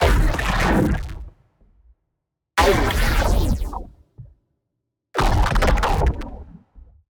Impact Blast 1
Impact-Blast-01-Example.mp3